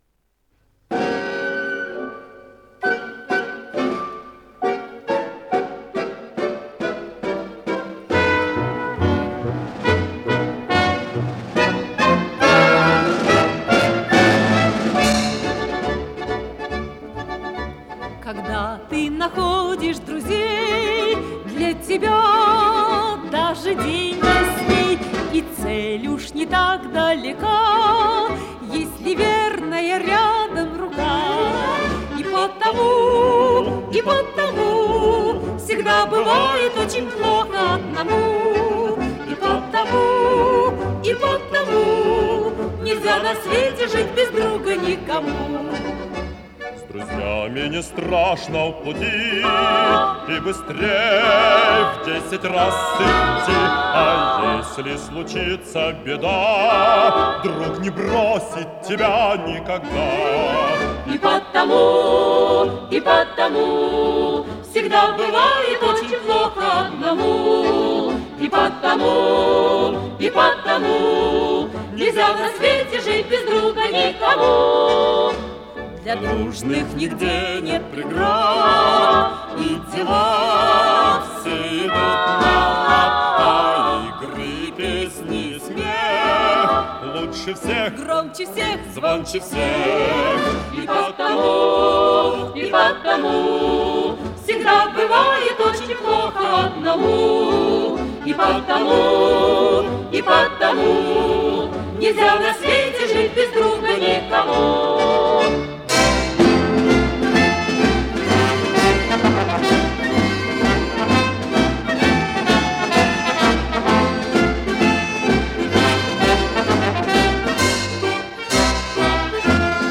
АккомпаниментИнструментальный ансамбль